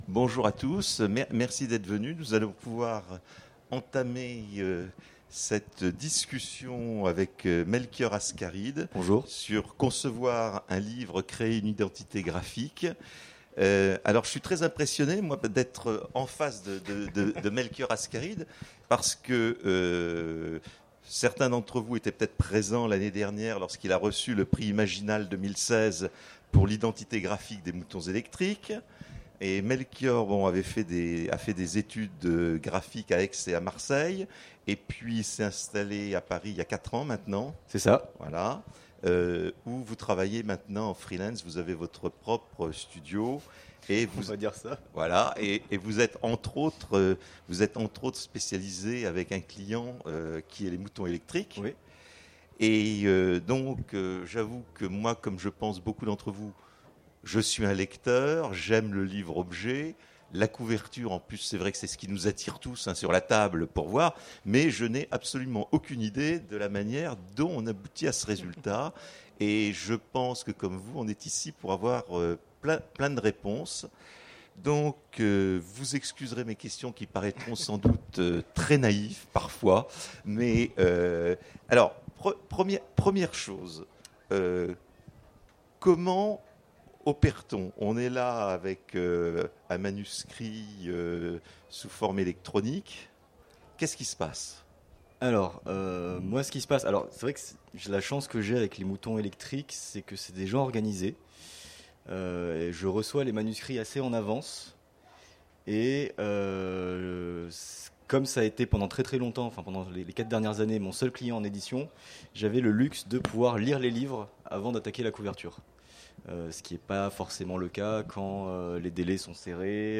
Imaginales 2017 : Conférence Concevoir un livre... créer une identité graphique !